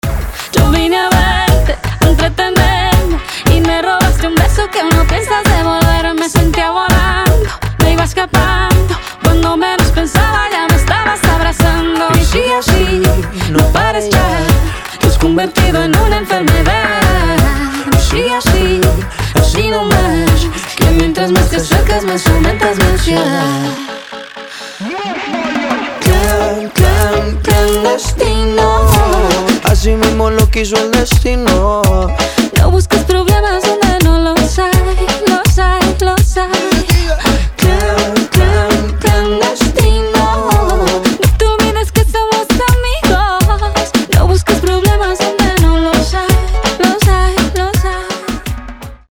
• Качество: 320, Stereo
заводные
дуэт
латиноамериканские
Reggaeton
Latin Pop